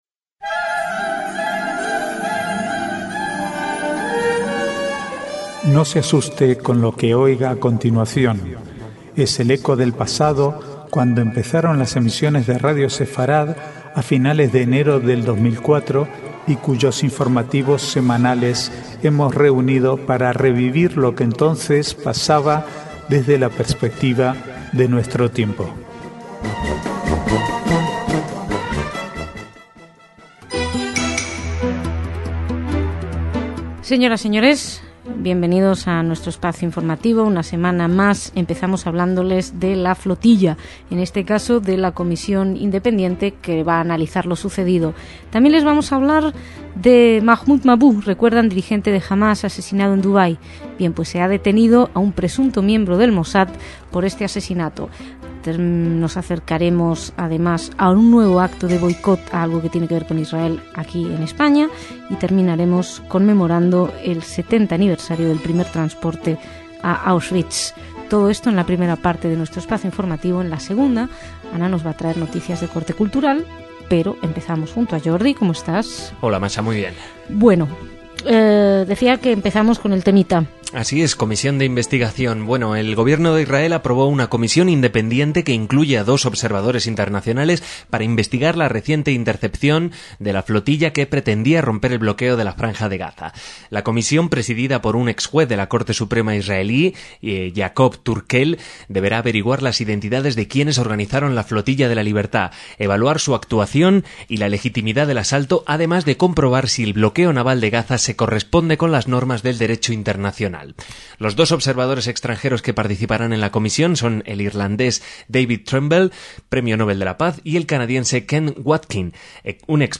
Archivo de noticias del 15 al 18/6/2010